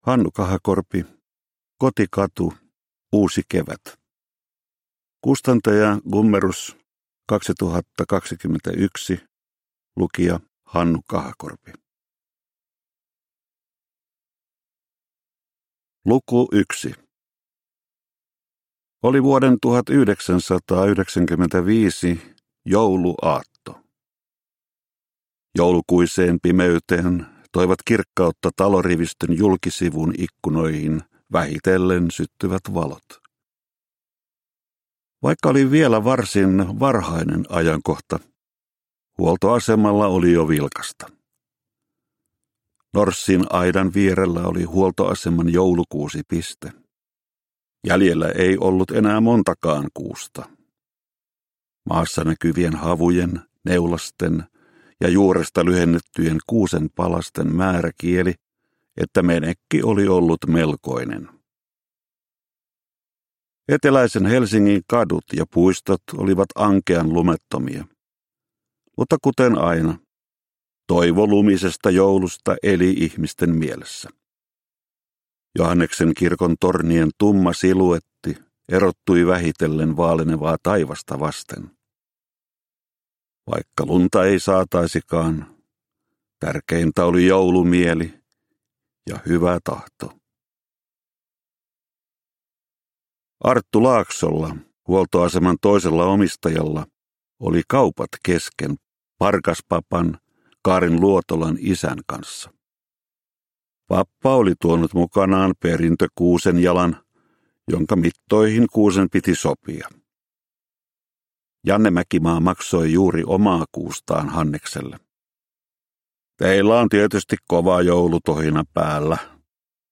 Kotikatu - Uusi kevät – Ljudbok – Laddas ner